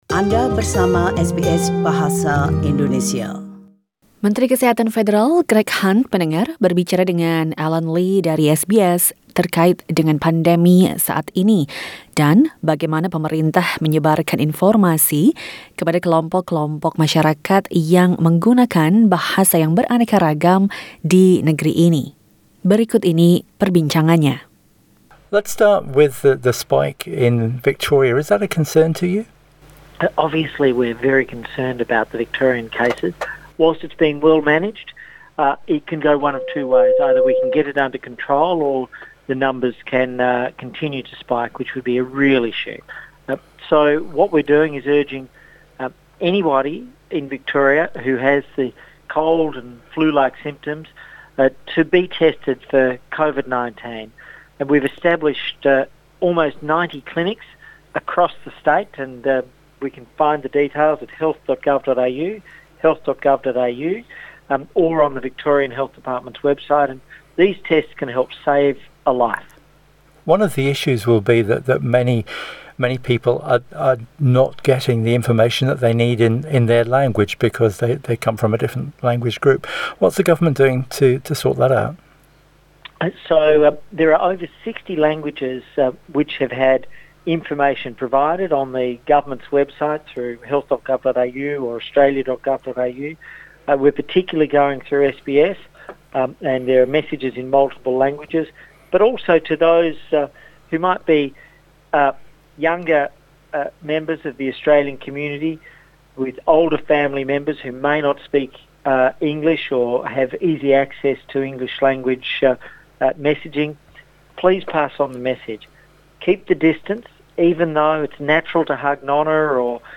Menteri Kesehatan Federal, Greg Hunt, berbicara